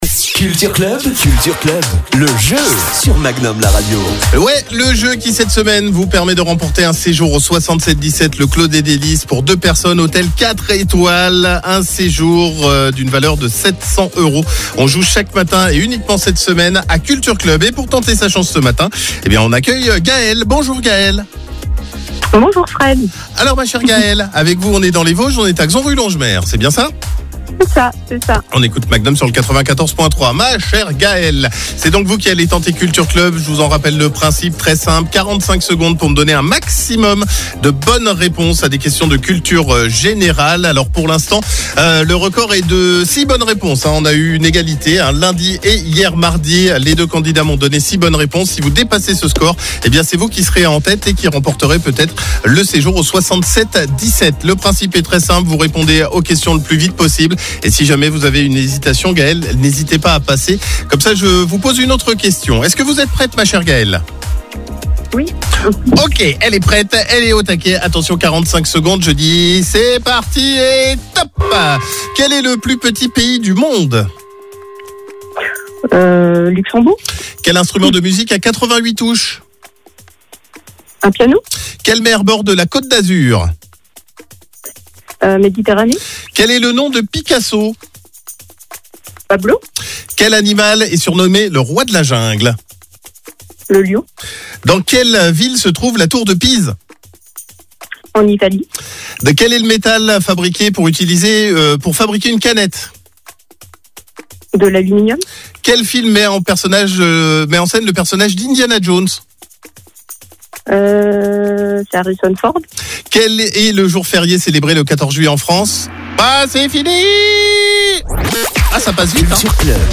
CULTURE CLUB , le jeu du CLUB MAGNUM qui vous permet de remporter des cadeaux exclusifs !